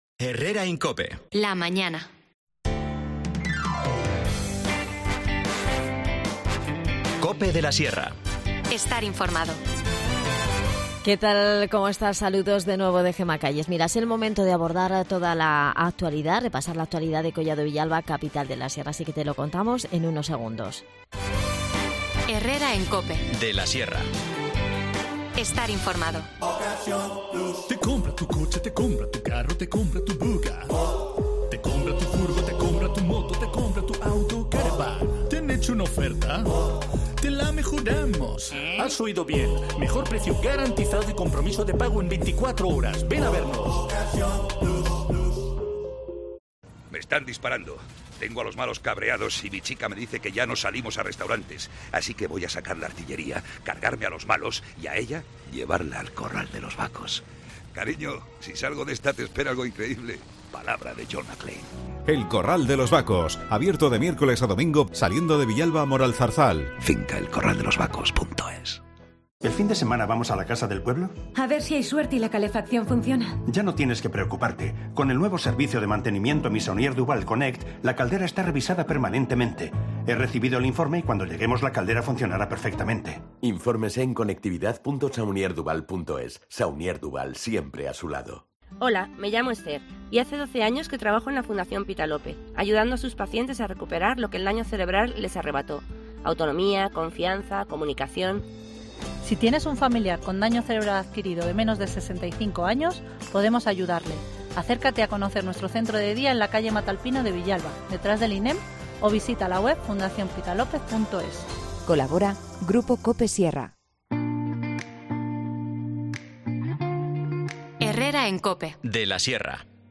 AUDIO: Repasamos con Adan Martínez, concejal de Comunicación en Collado Villalba, Capital de la Sierra, toda la actualidad del municipio que...